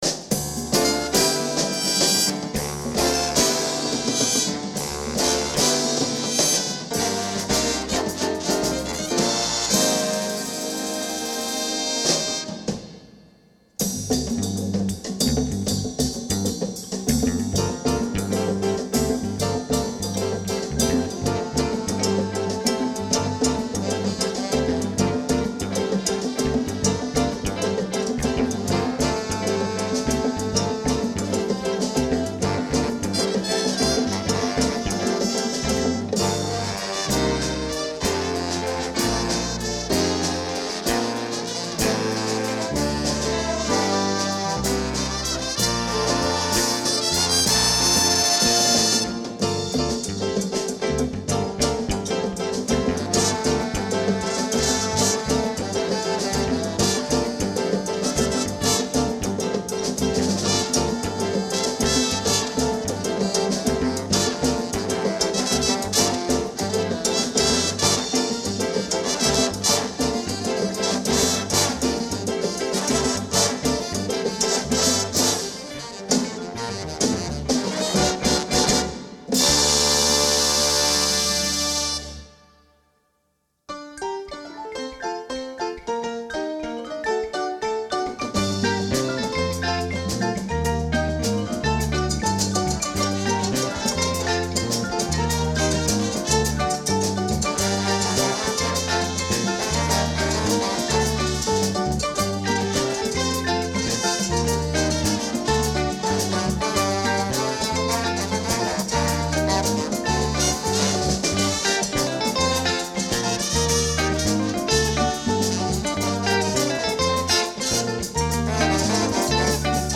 Here is the April 7, 2017 World Debut recording of "Nanigo" from the Jazz at the Rock festival.
The All-Council Rock Jazz Band consisted of the lead and senior members of both the Council Rock North and South Jazz Bands.